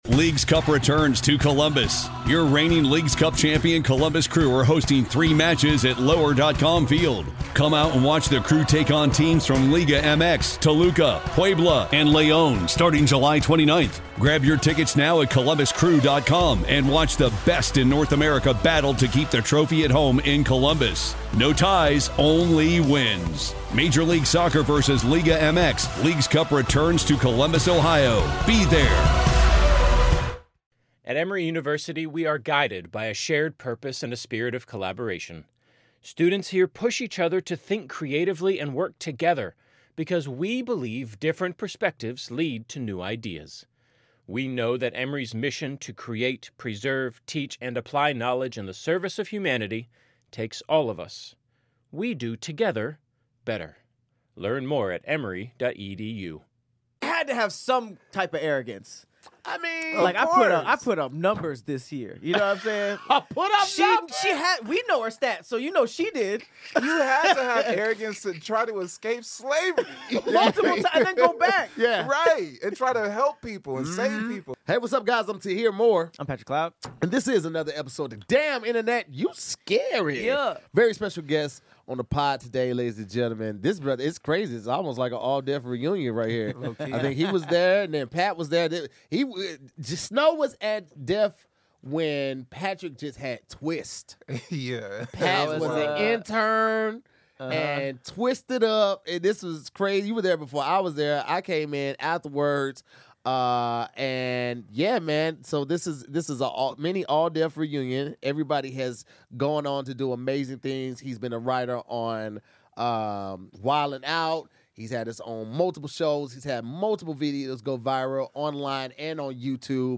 comedians
conversation. They explore the journey of filming a one-take comedy special, writing for top shows like "Wild ‘n Out" and HBO’s "Game Theory," and the struggles of maintaining creative control in entertainment.